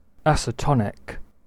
Ääntäminen
UK RP : IPA : /ˈæsɛˌtɒnɪk/